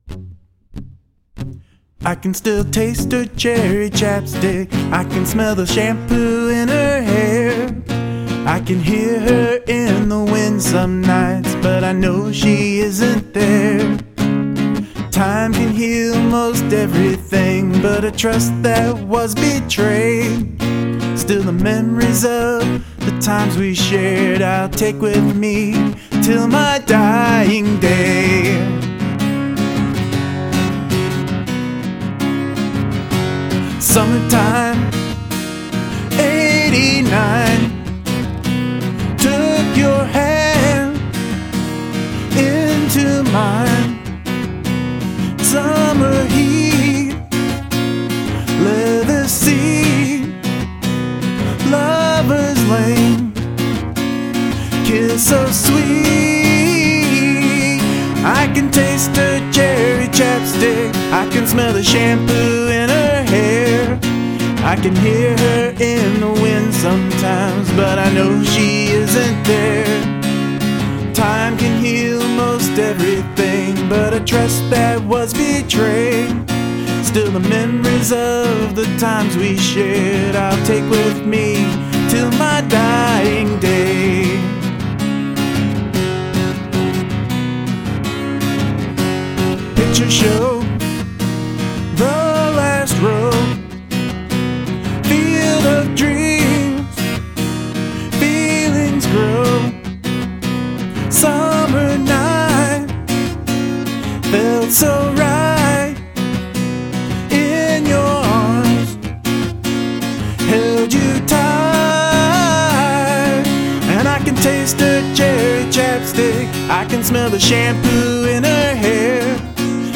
Recorded using: Boss BR-900
Yahama Acoustic/Electric
AT2020 Condenser Mic
Simple but so effective.